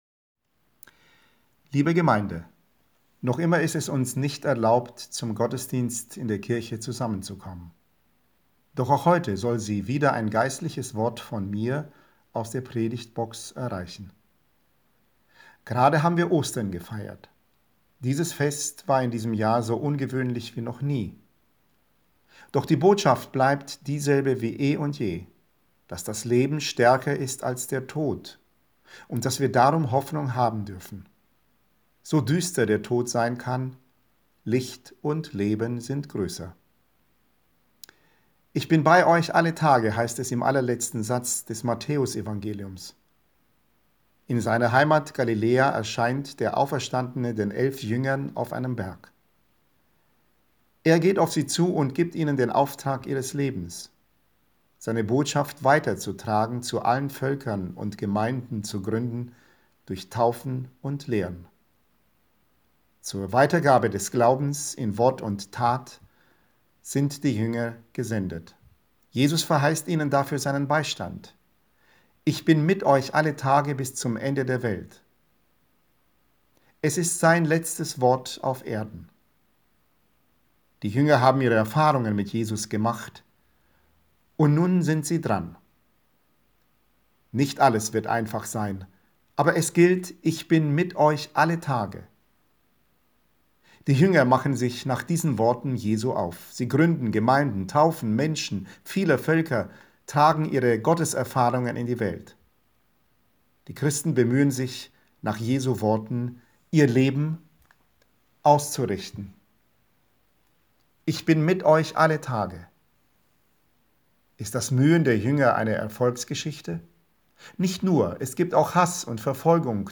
Predigt Sonntag Quasimodogeniti 2020
Predigt am Sonntag Quasimodogeniti 2020.mp3